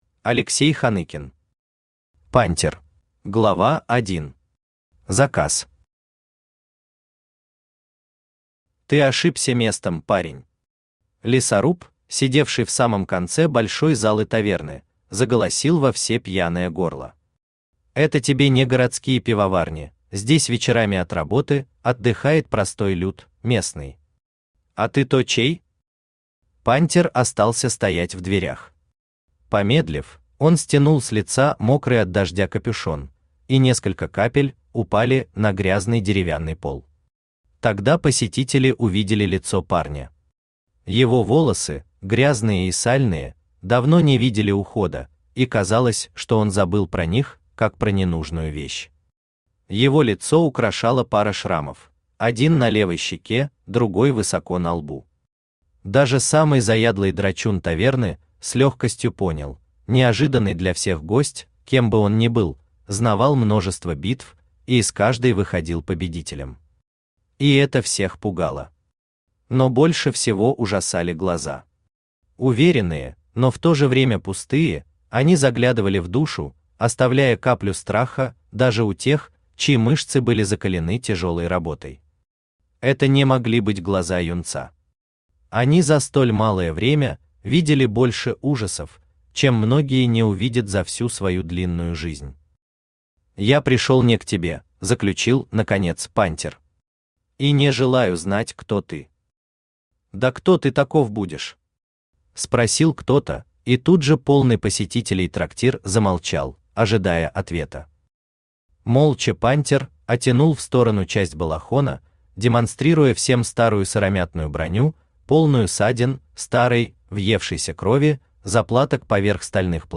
Aудиокнига Пантир Автор Алексей Юрьевич Ханыкин Читает аудиокнигу Авточтец ЛитРес.